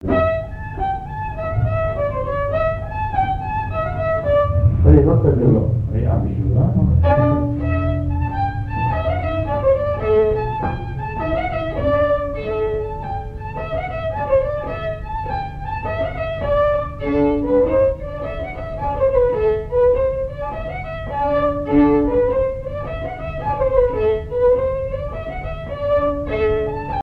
Mémoires et Patrimoines vivants - RaddO est une base de données d'archives iconographiques et sonores.
danse : branle : avant-deux
Airs à danser aux violons
Pièce musicale inédite